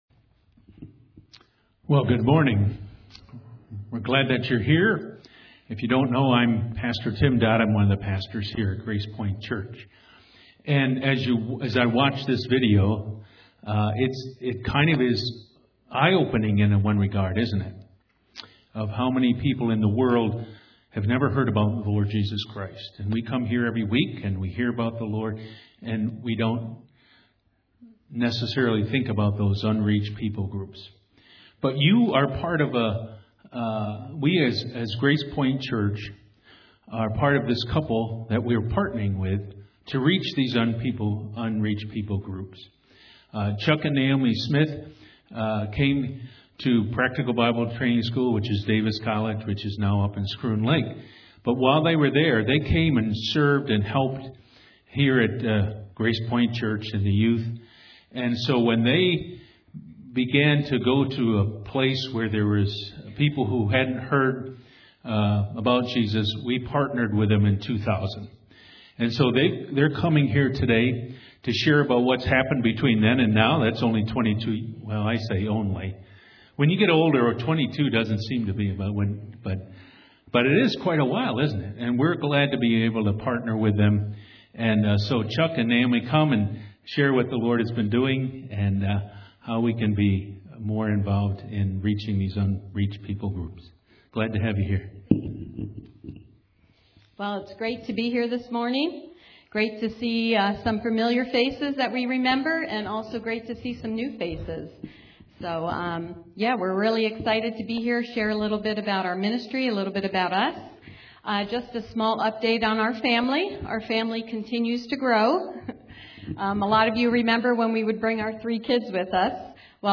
Series: 2022 Sermons